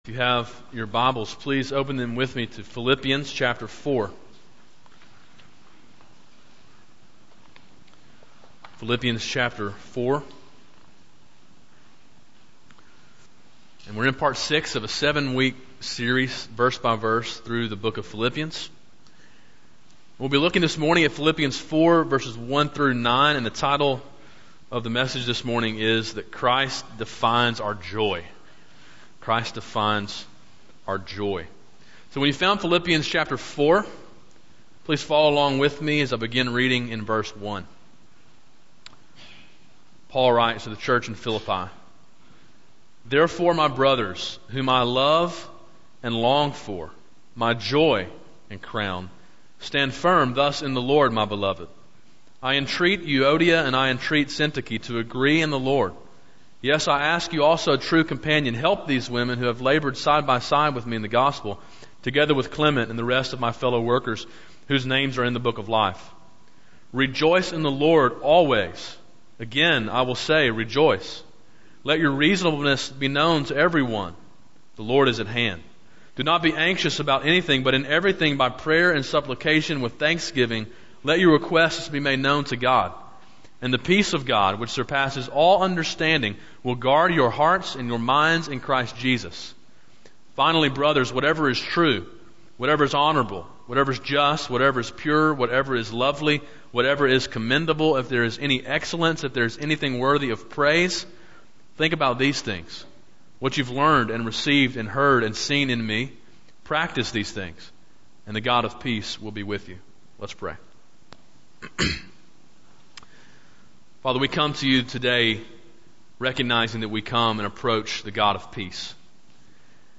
A sermon in a series entitled To Live Is Christ: Verse by Verse through the Book of Philippians. Main point: To live for Christ, we must work to have joy in the Lord.